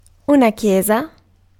Ääntäminen
UK : IPA : [tʃɜːtʃ] US : IPA : [tʃɝtʃ]